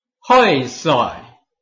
臺灣客語拼音學習網-客語聽讀拼-饒平腔-開尾韻
拼音查詢：【饒平腔】hoi ~請點選不同聲調拼音聽聽看!(例字漢字部分屬參考性質)